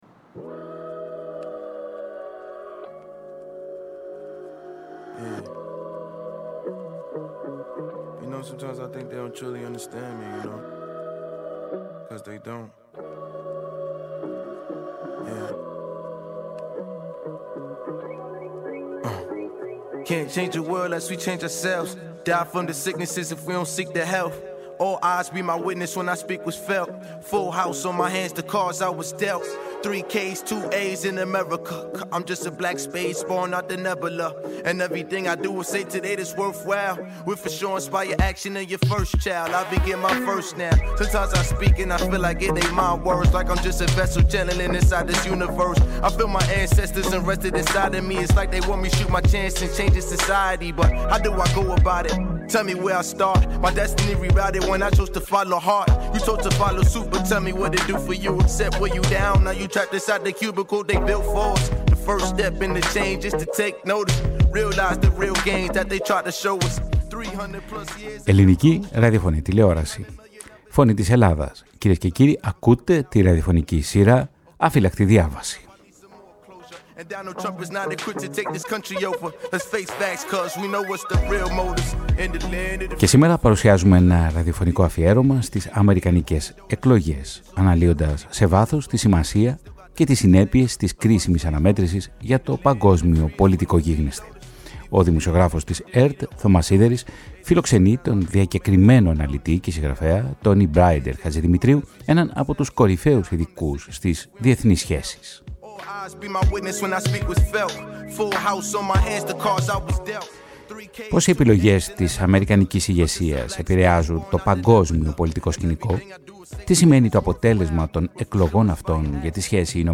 Η ραδιοφωνική σειρά “Αφύλαχτη Διάβαση” παρουσίασε ένα ειδικό θεματικό podcast στην οποία αναλύθηκε σε βάθος η σημασία και οι συνέπειες των κρίσιμων αμερικανικών εκλογών για το παγκόσμιο πολιτικό γίγνεσθαι.